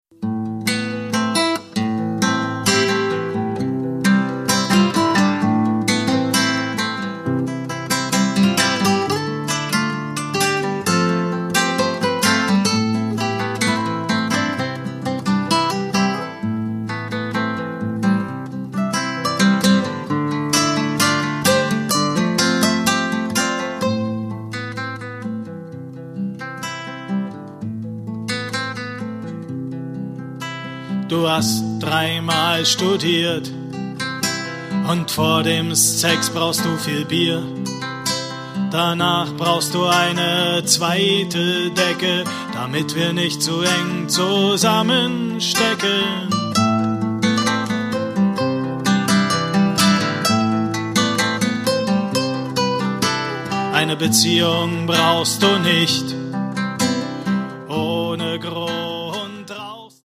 Gitarre, Flöte, Blockflöte, Klavier, Gesang